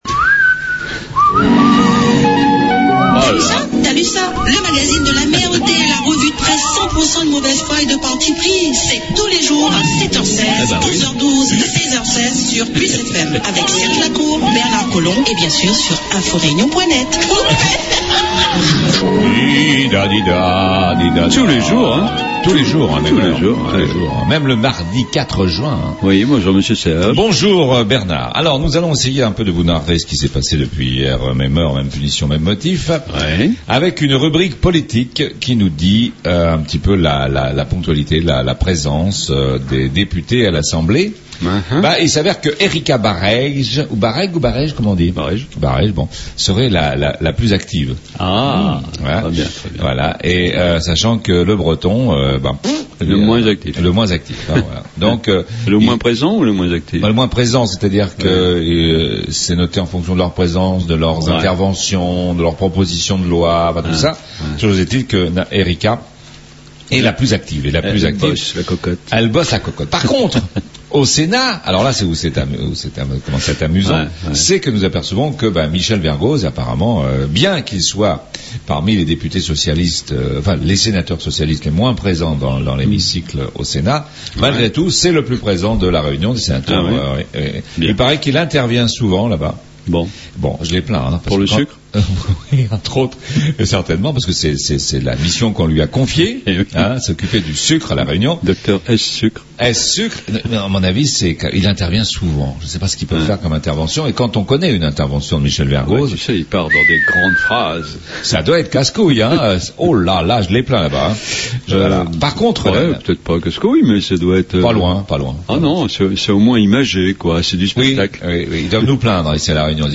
La revue de presse du mardi 4 Juin 2013 , "Thalussa"..